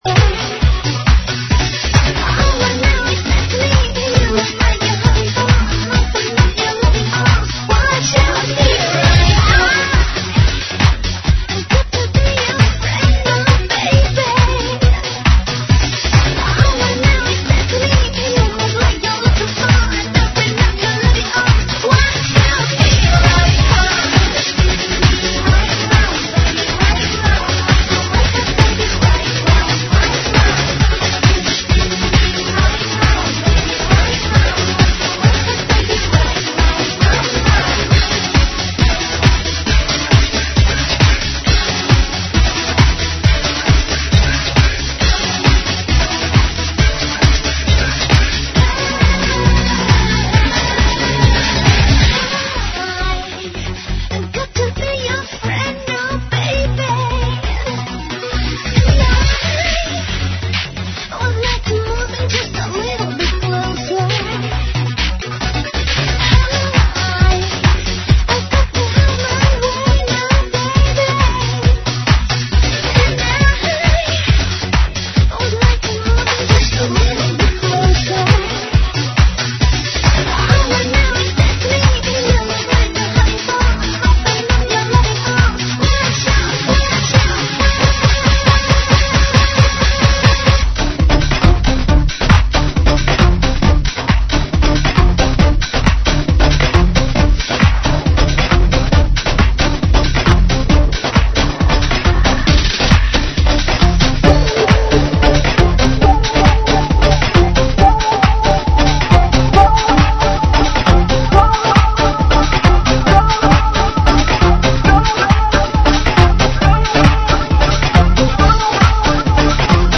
GENERO: POP – REMIX
AEROBICS (STEP-HILOW)